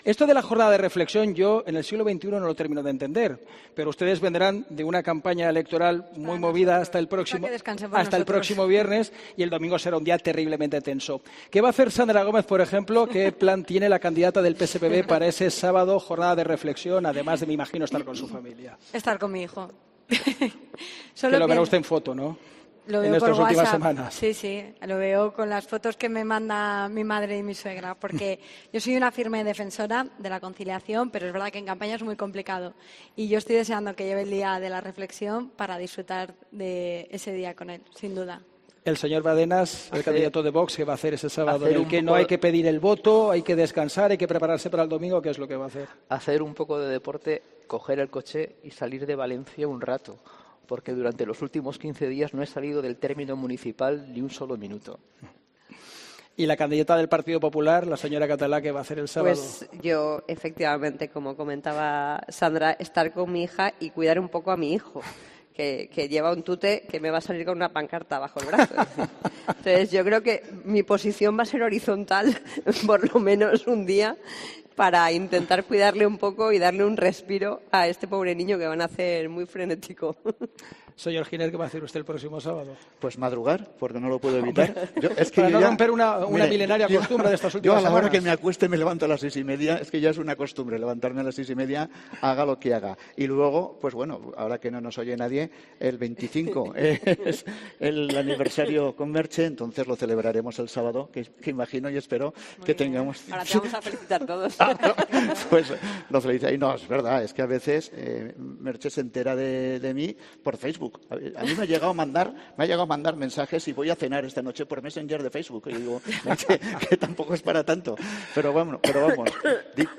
De esta forma distendida y en un tono más amable concluían los 'Debate Clave´ organizados por COPE y Las PROVINCIAS con los candidatos a la alcaldía de Valencia o a la presidencia de la Generalitat.